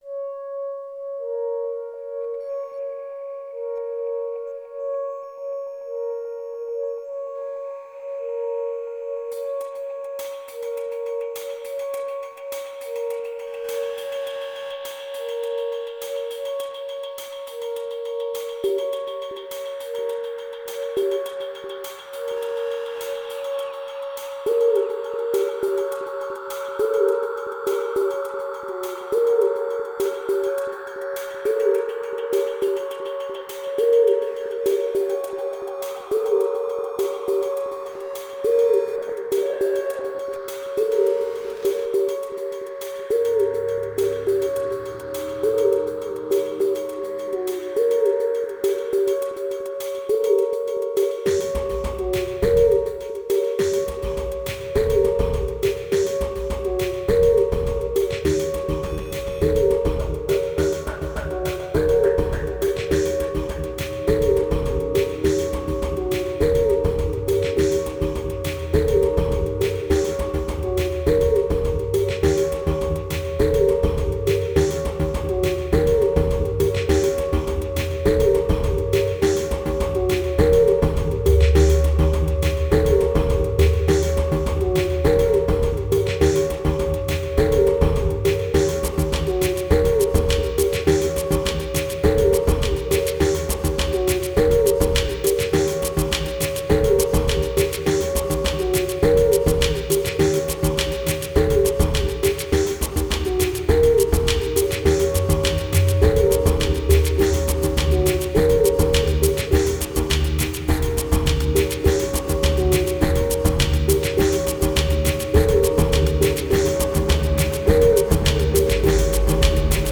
1702📈 - 82%🤔 - 103BPM🔊 - 2014-05-01📅 - 317🌟